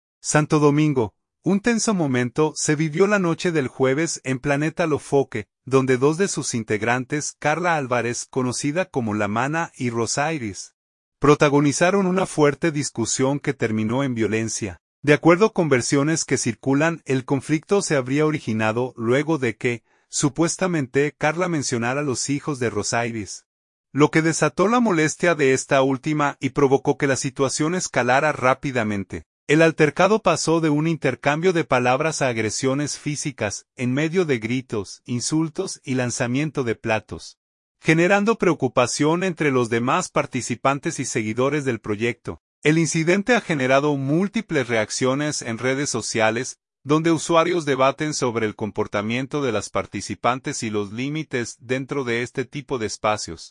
El altercado pasó de un intercambio de palabras a agresiones físicas, en medio de gritos, insultos y lanzamiento de platos, generando preocupación entre los demás participantes y seguidores del proyecto.